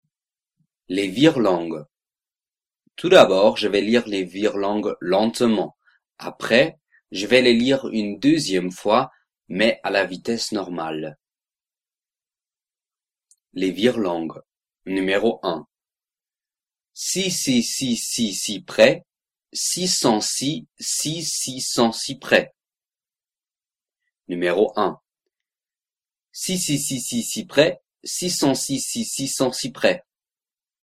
01 Virelangue
Le numérotage des virelangues suit la fiche de travail. Le virelangue va être prononcé deux fois, une fois lentementent et après à la vitesse normale.